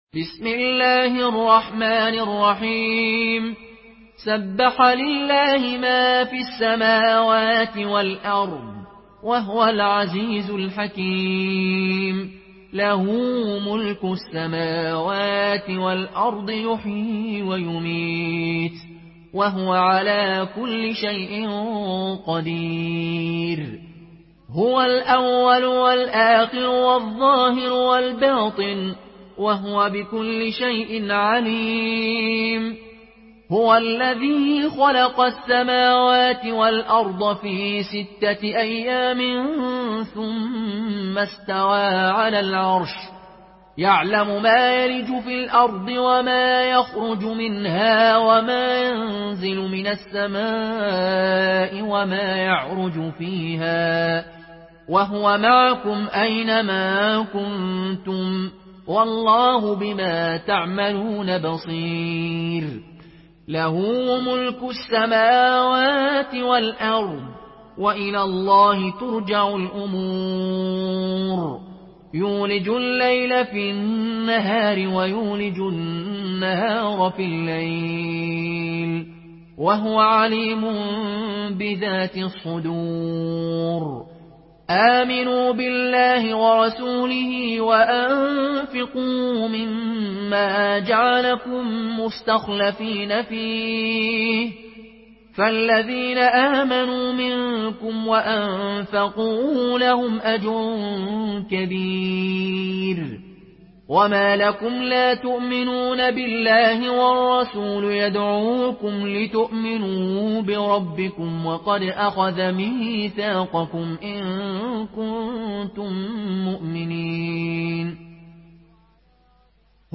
Une récitation touchante et belle des versets coraniques par la narration Qaloon An Nafi.
Murattal Qaloon An Nafi